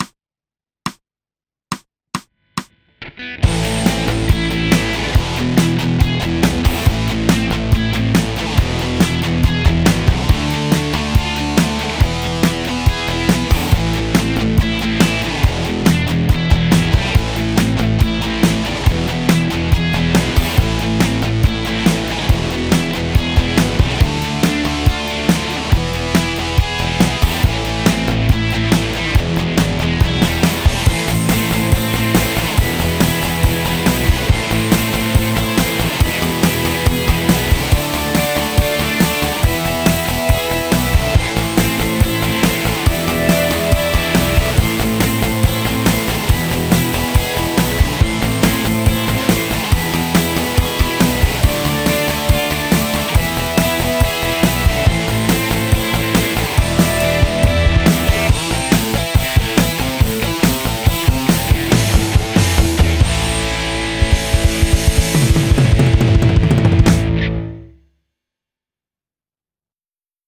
ハーモニック・マイナー・スケール ギタースケールハンドブック -島村楽器